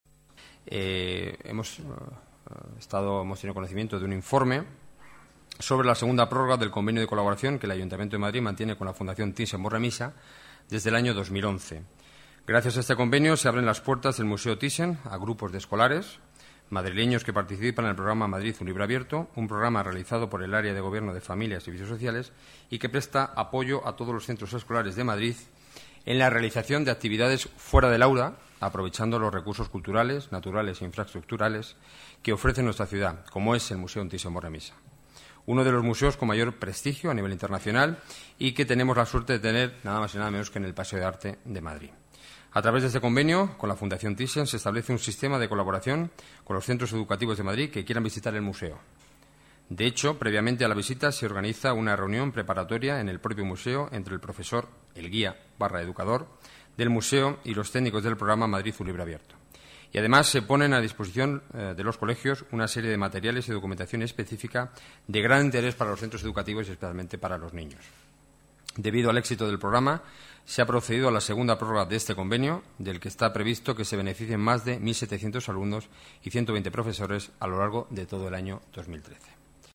Nueva ventana:Declaraciones del delegado de Seguridad y Emergencias, Enrique Núñez: Madrid, libro abierto Thyssen